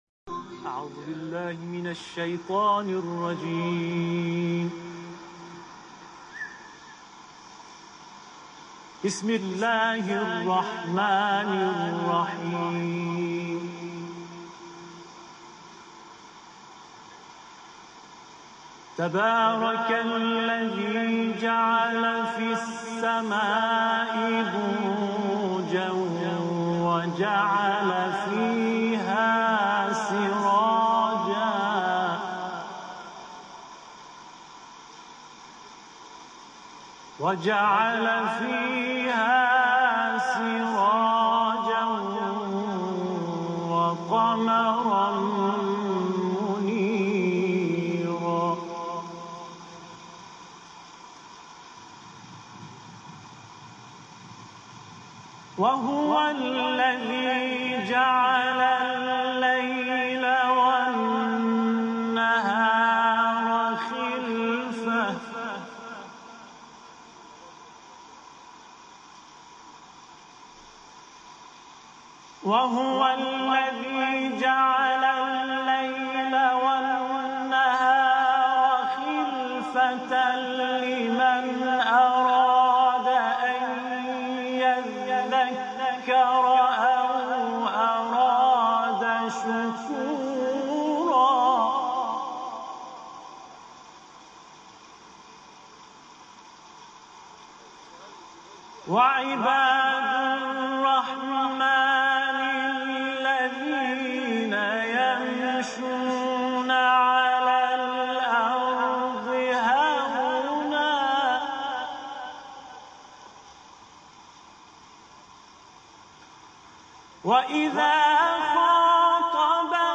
صوت تلاوت
قاری ، سوره فرقان